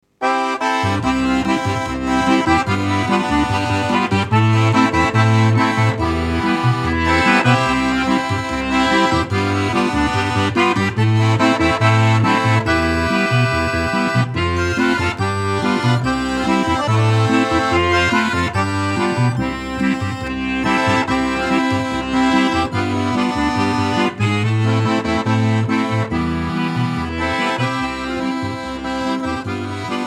Recorded at Stebbing Recording Studios